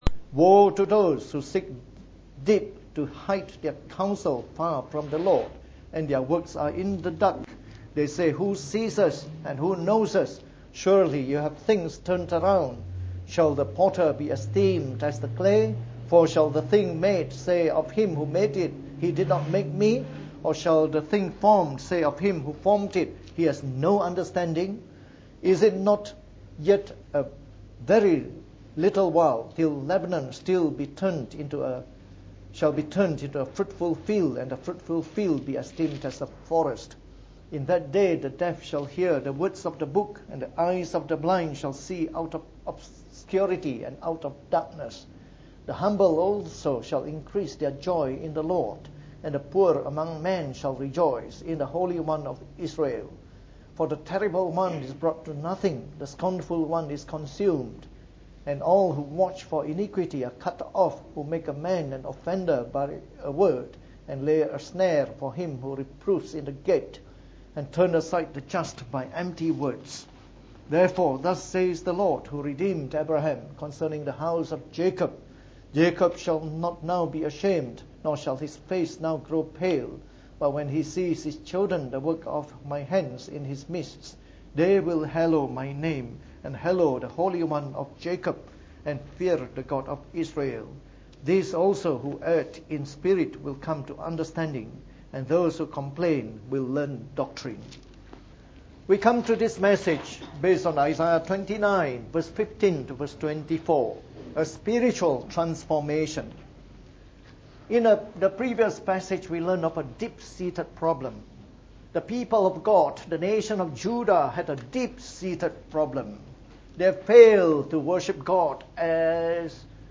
From our series on the book of Isaiah delivered in the Morning Service.